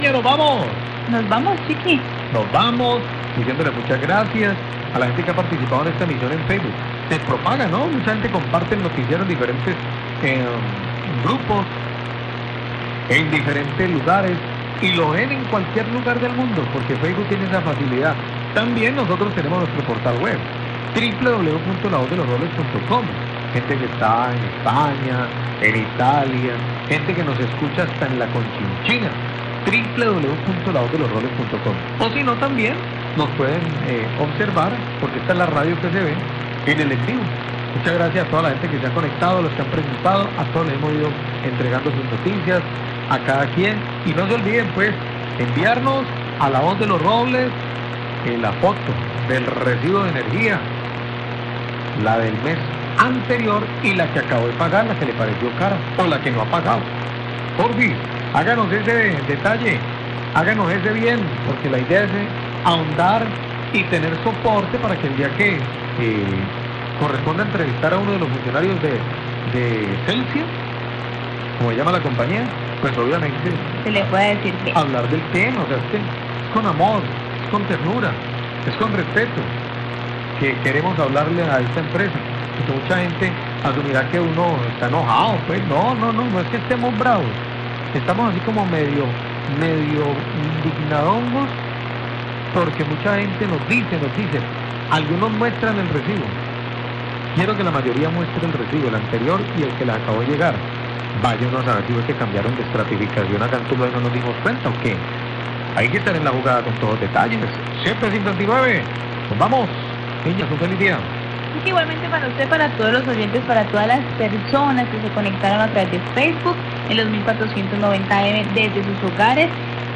Radio
Periodistas de La voz de los robles le solicitan a la comunidad enviar fotografías del recibo actual de energía y el recibo pasado para hacer la comparación en el incremento del valor y tener un soporte para adelantar el respectivo reclamo a la empresa de energía.